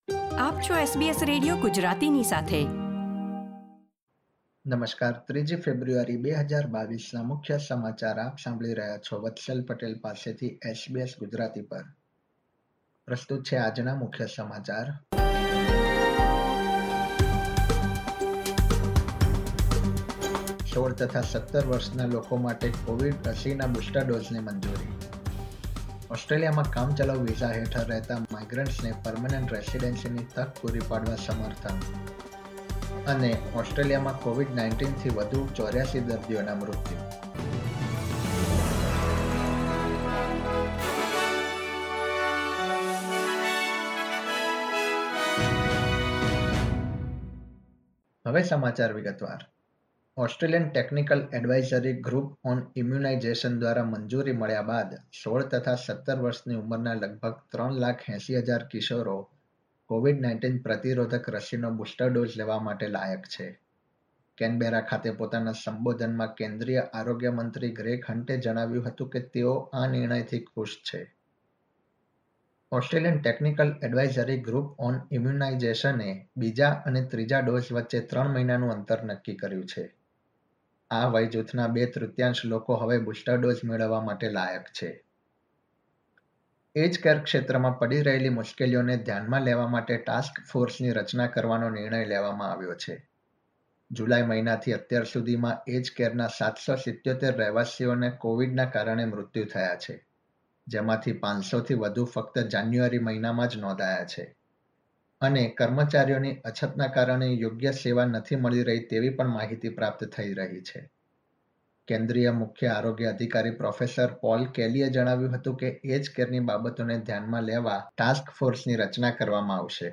SBS Gujarati News Bulletin 3 February 2022